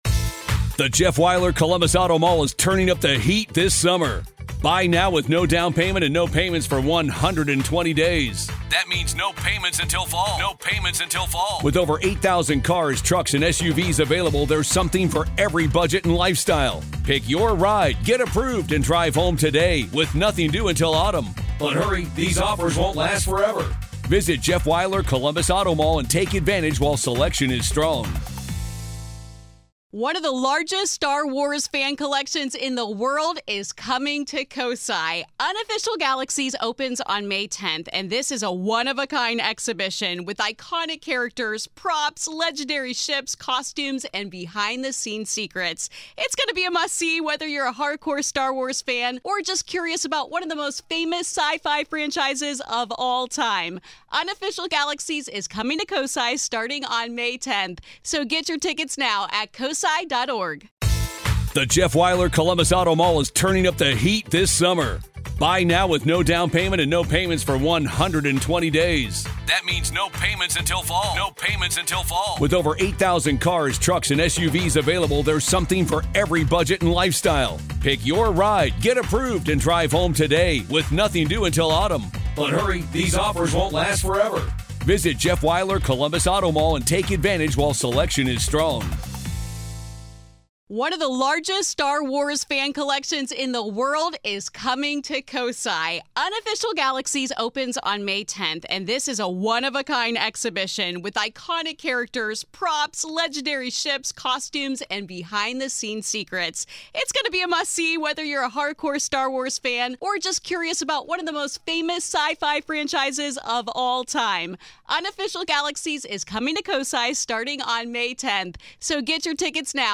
In this episode, we delve into their approach to paranormal investigations, the tools they use, and the chilling locations they continue to explore in their quest for answers. This is Part Two of our conversation.